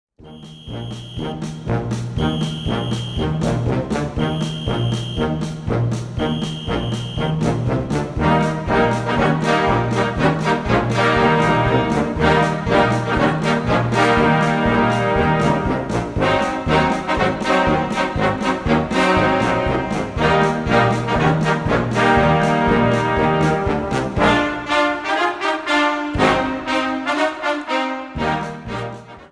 Categoria Concert/wind/brass band